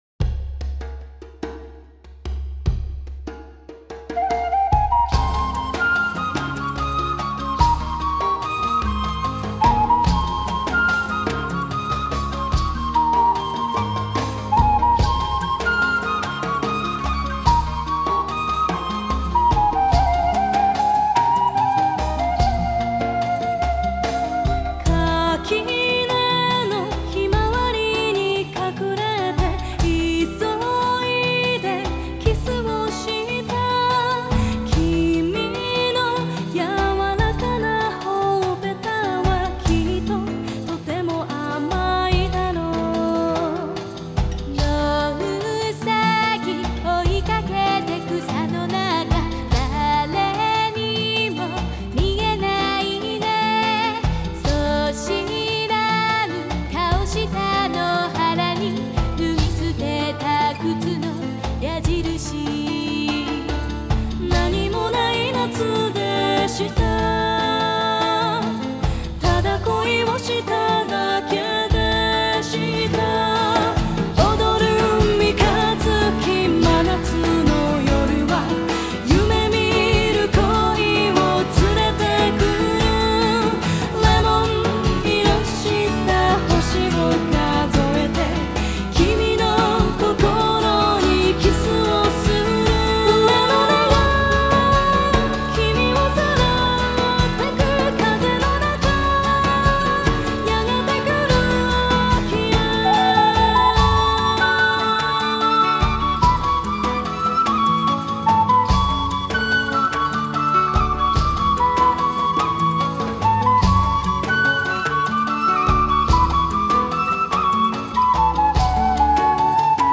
Genre: J-Pop, Female Vocal